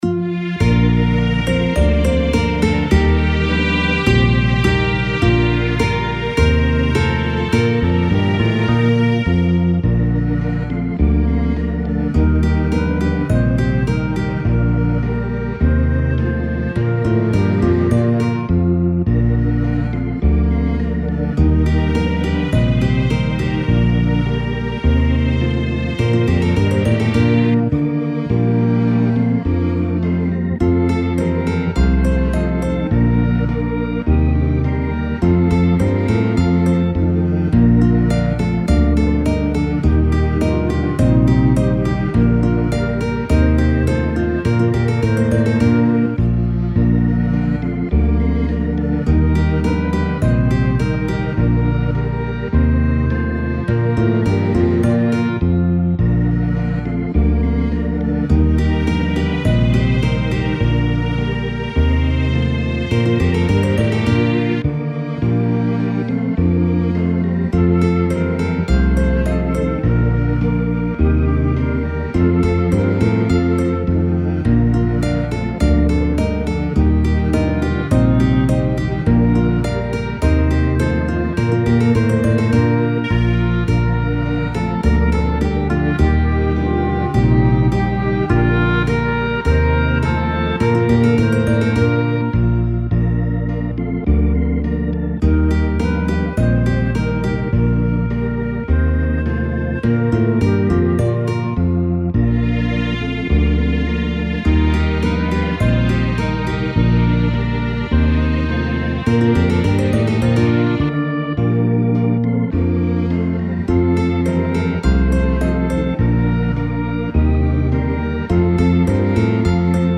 논밭에 오곡백과 (연, 반주, PPT. 221123)